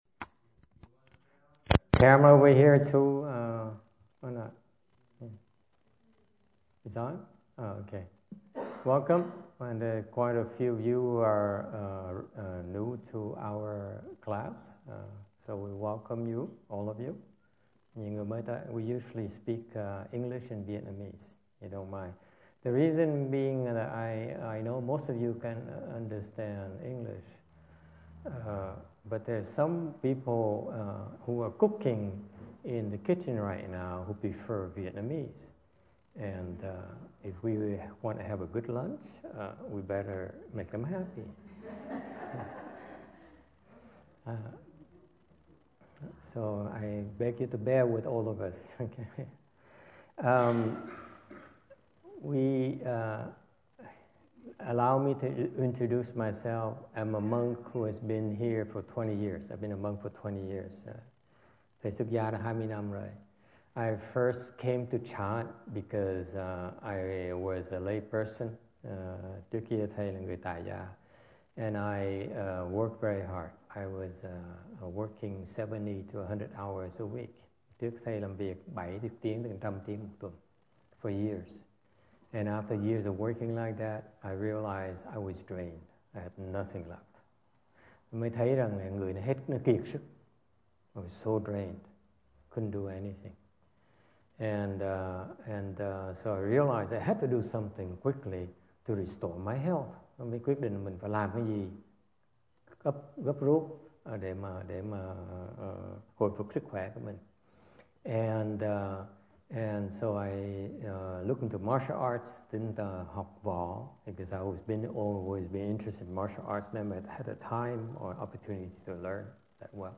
No Cut